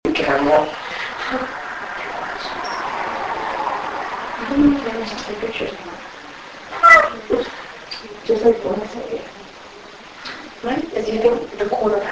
Private Residences
“Ghost Cat” Throughout the recording from this investigation, we kept getting what sounded like cat meows
Meow.mp3